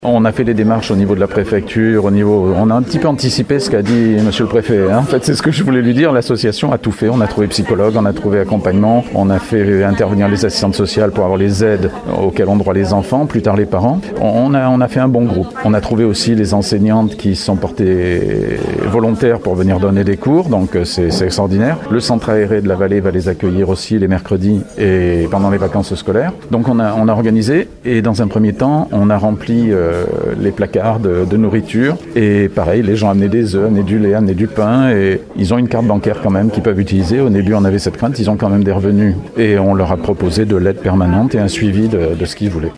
Les huit enfants âgés de 5 à 17 ans et leurs parents ont pu trouver refuge dans un gîte proposé par la commune. François Cristou, conseiller municipal et président de l’association « La Vallée ça vit », les a aidés à s’installer.